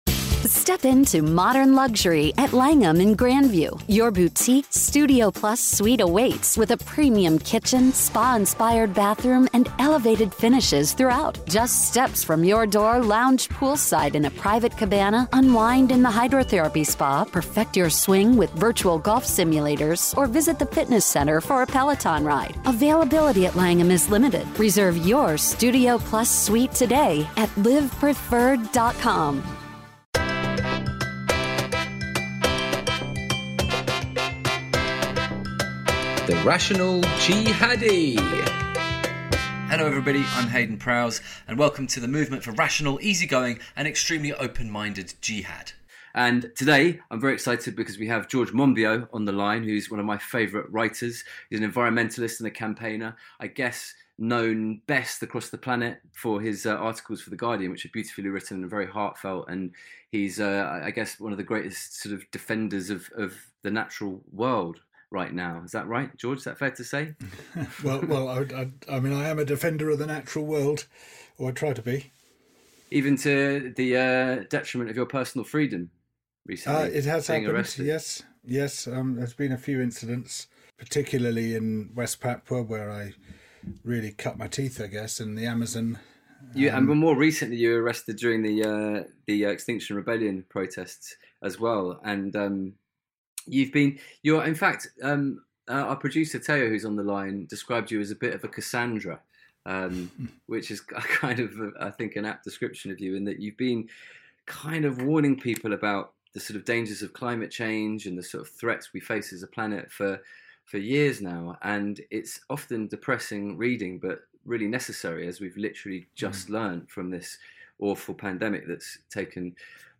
As we come out of this truly Biblical plague, I ask George Monbiot what other potentially apocalyptic threats we face as a species and as a planet and how we might avoid them.